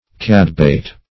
cadbait - definition of cadbait - synonyms, pronunciation, spelling from Free Dictionary Search Result for " cadbait" : The Collaborative International Dictionary of English v.0.48: Cadbait \Cad"bait`\, n. [Prov.